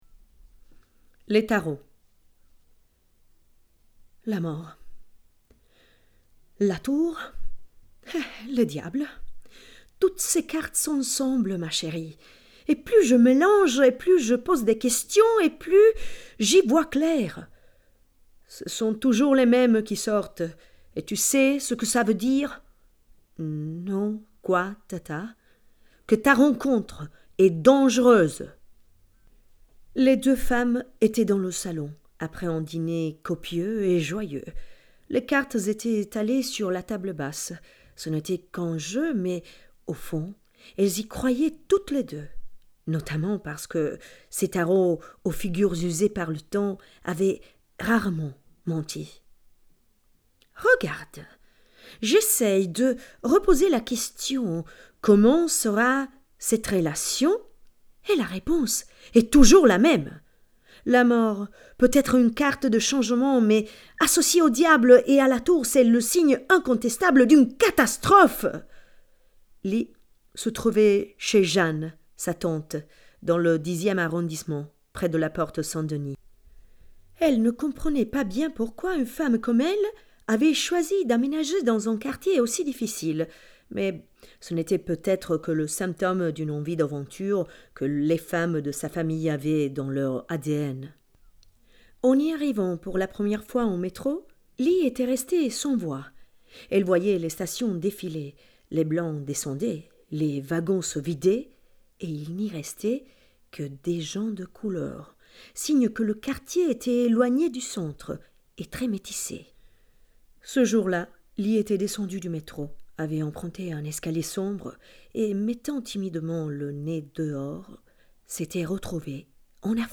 “Les Tarots”, brano audio tratto dal romanzo L’amante siriano di Rosita Ferrato nella versione francese di recente pubblicazione.